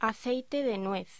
Locución: Aceite de nuez